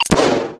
chopperDrop.ogg